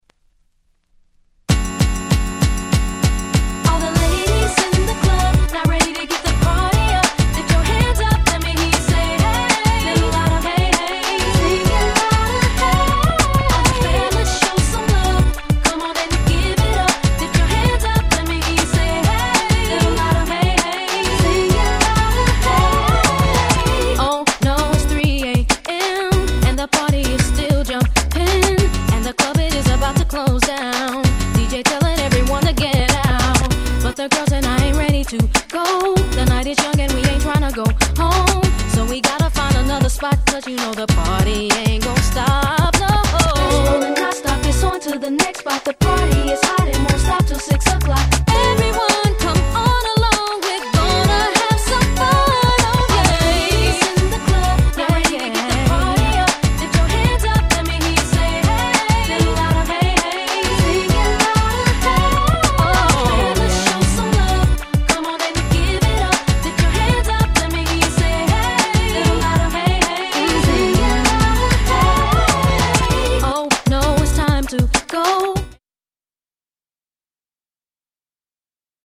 底抜けにキャッチーな曲調で、当時Blaque『As If』なんかと一緒にプチヒットしたNice R&B♪